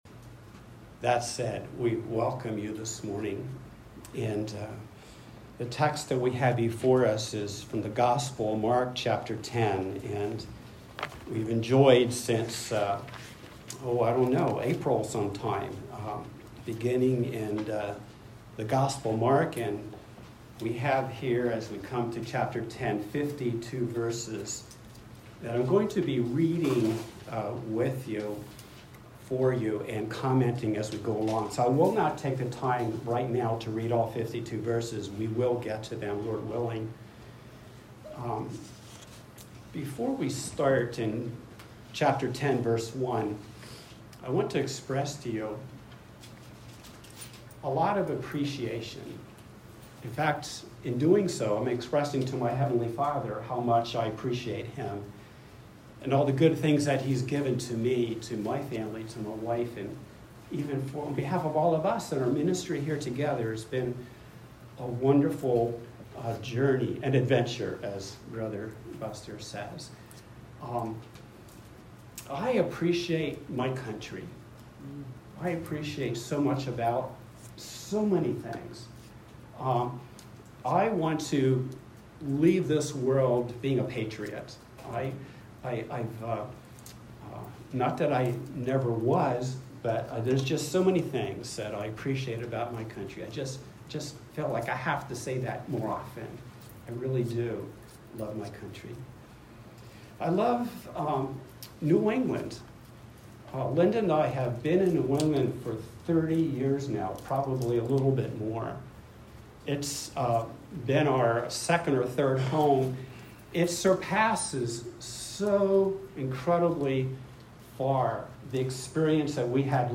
Mark 10:1-52 Service Type: Sunday Worship « Going Forward Mark 9:14